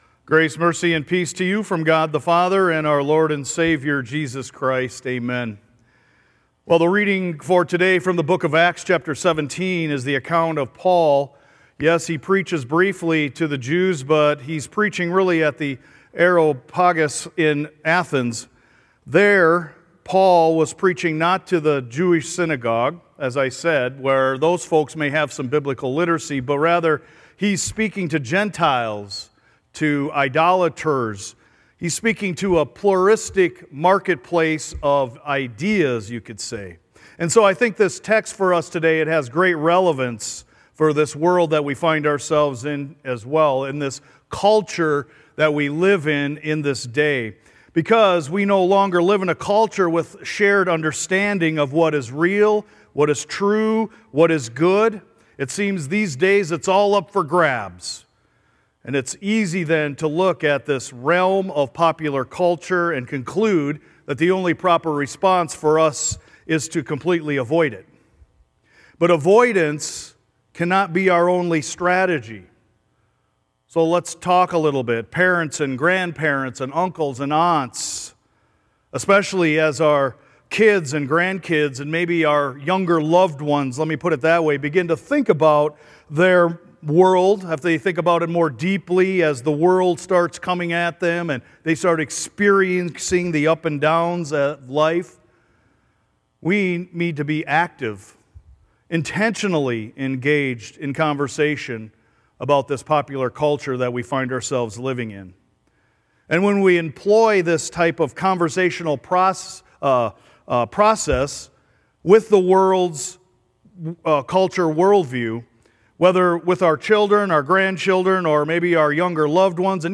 sermon-march-9-2025.mp3